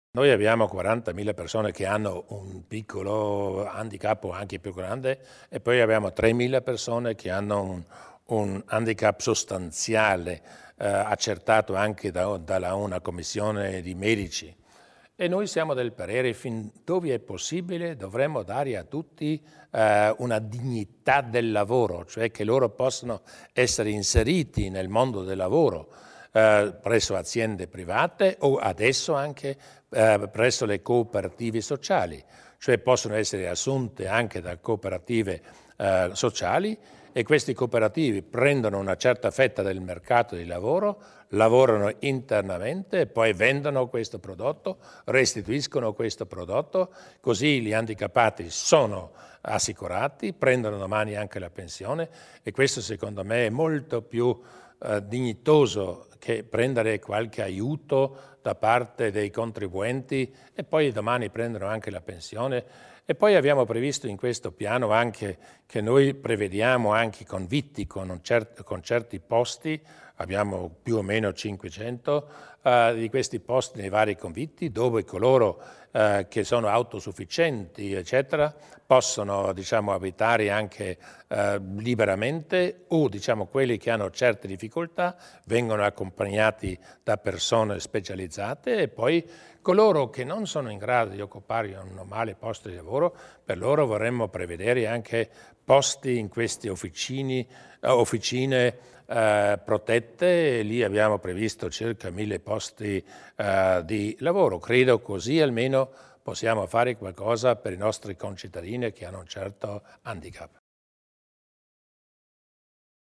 Il Presidente Durnwalder spiega gli interventi a favore dei portatori di handicap